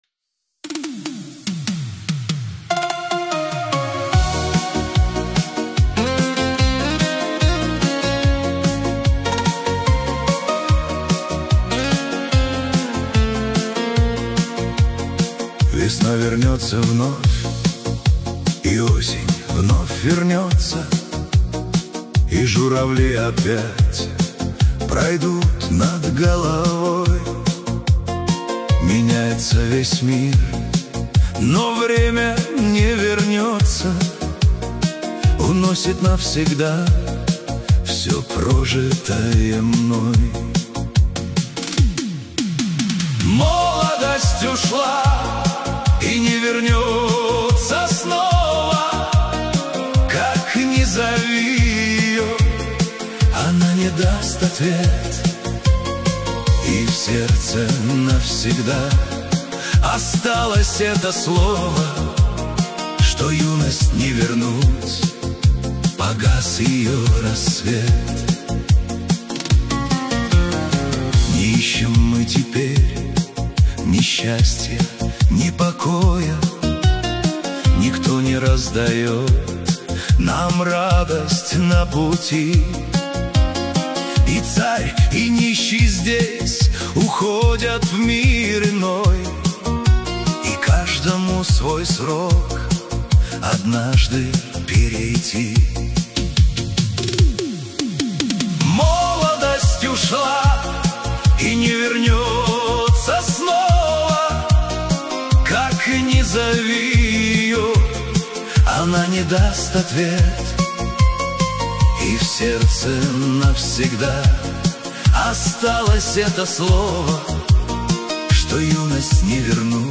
песню